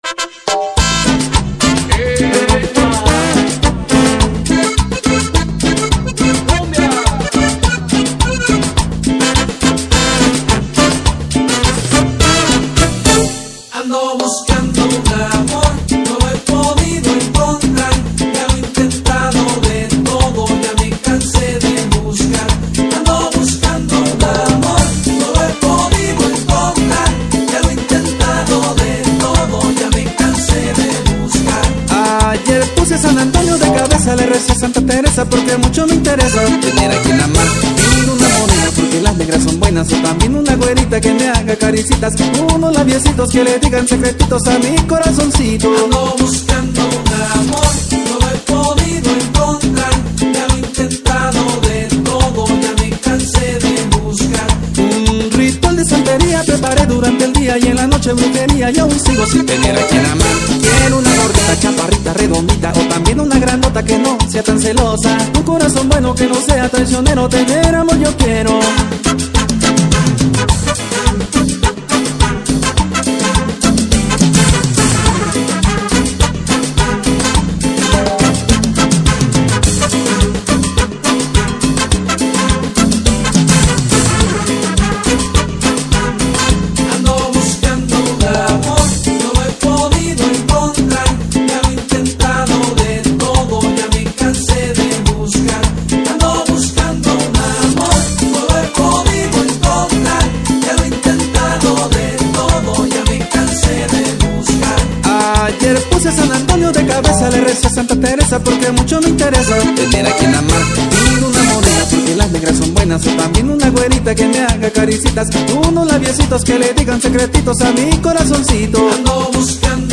musica tropical/sonidera